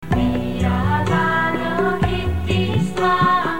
1_3_man.mp3